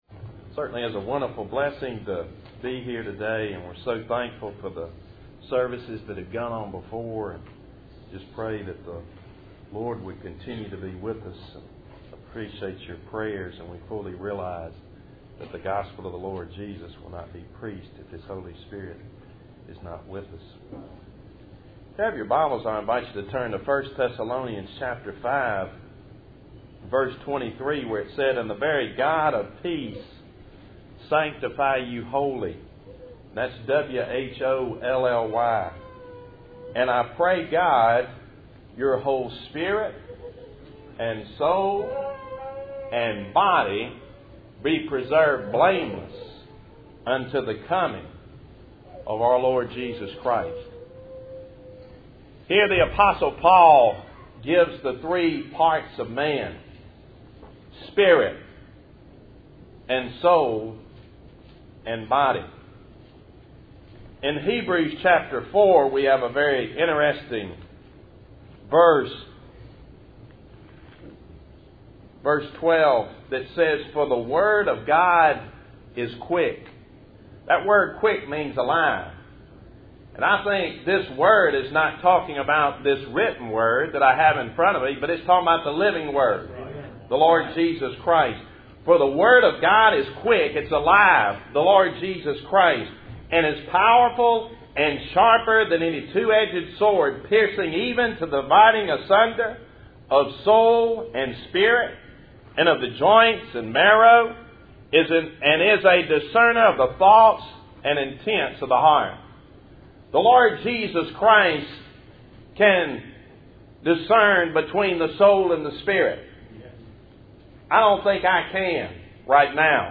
Service Type: Ebenezer Fellowship Meeting